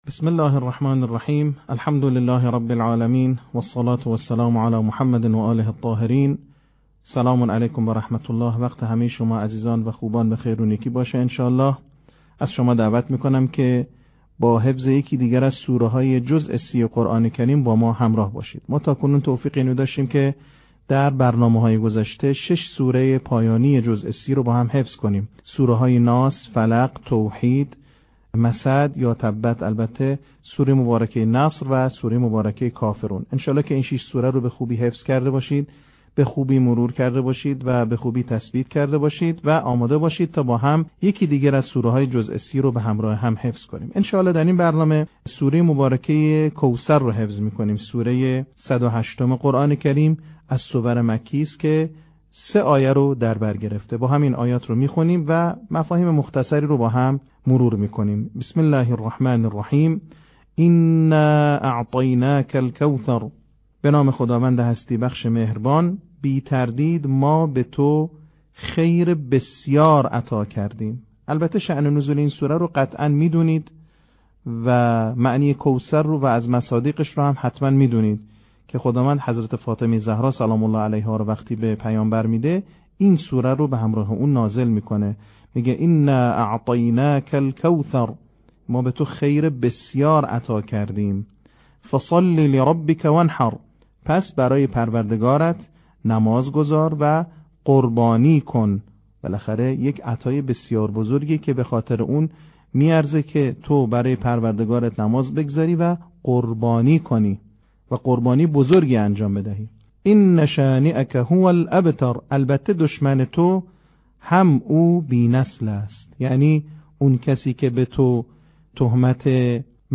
صوت | آموزش حفظ سوره کوثر